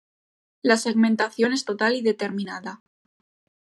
Read more Adj Adv Noun Frequency B1 Hyphenated as to‧tal Pronounced as (IPA) /toˈtal/ Etymology Borrowed from Medieval Latin tōtālis, from tōtus (“all, whole, entire”) + -ālis.